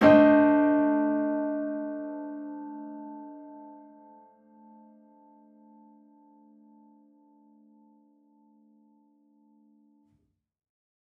Index of /musicradar/gangster-sting-samples/Chord Hits/Piano
GS_PiChrd-Amin7+9.wav